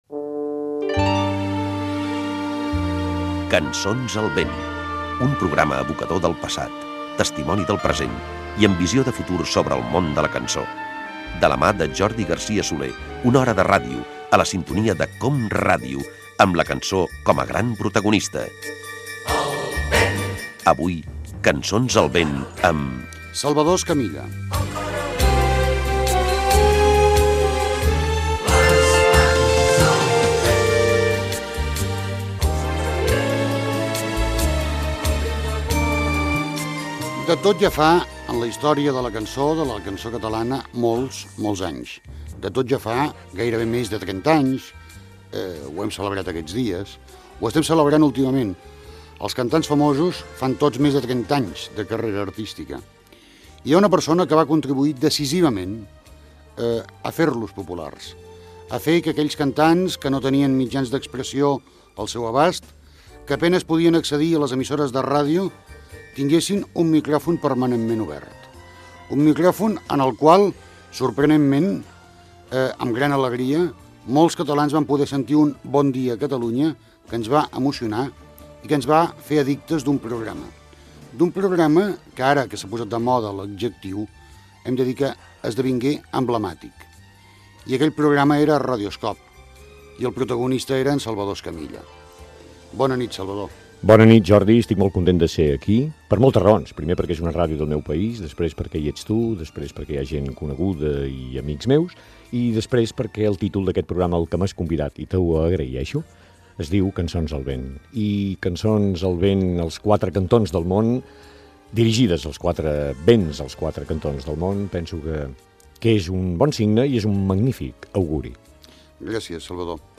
Careta del programa, presentació, entrevista al radiofonista i showman Salvador Escamilla, creador del programa "Radio-scope".
Divulgació